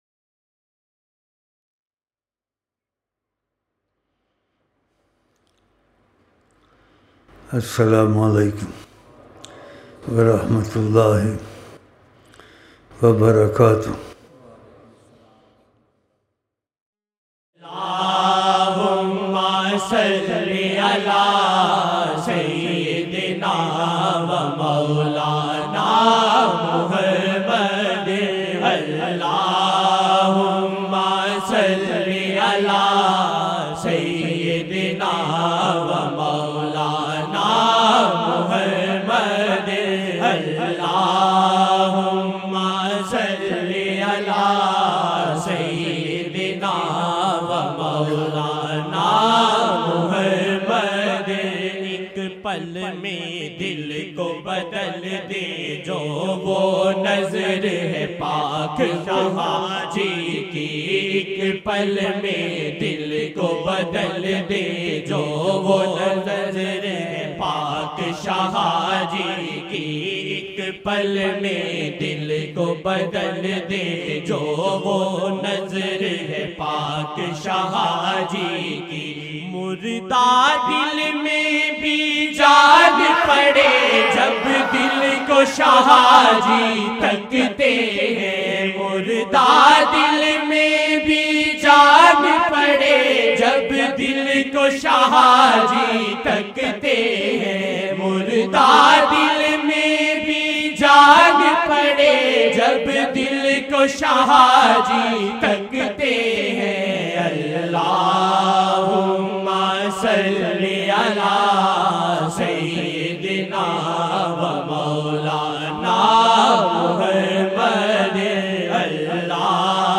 15-March-2013-Israq-Mehfil-02 jaamadi ul awal 1434
Naat Shareef